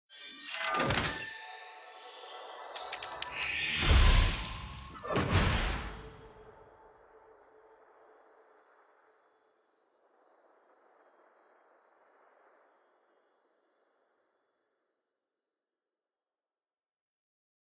sfx-clash-capsule-tier-0-ante-3.ogg